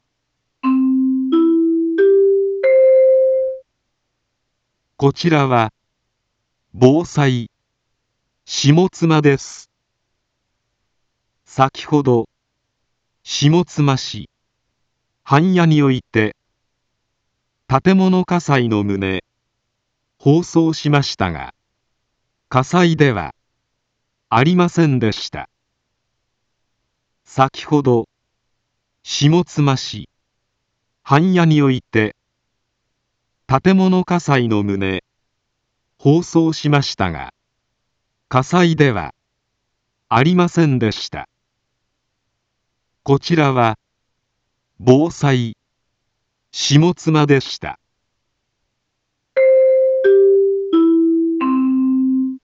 一般放送情報
BO-SAI navi Back Home 一般放送情報 音声放送 再生 一般放送情報 登録日時：2025-10-20 14:08:13 タイトル：誤報 インフォメーション：こちらは、防災、下妻です。 先程、下妻市、半谷において、 建物火災の旨、放送しましたが、火災では、ありませんでした。